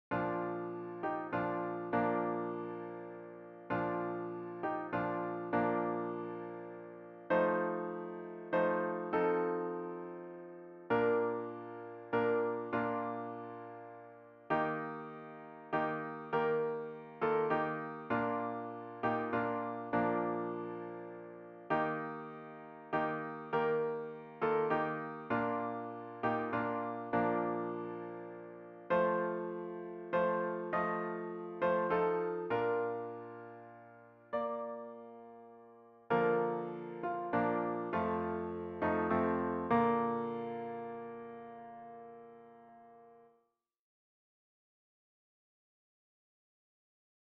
Fichiers pour répéter :
Douce nuit 4 voix